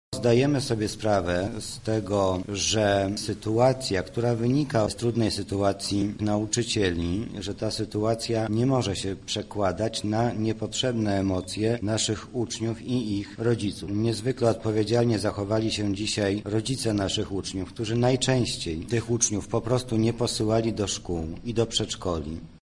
Zależy nam aby protest nauczycieli odbywał się spokojnie – mówi Mariusz Banach, zastępca prezydenta miasta.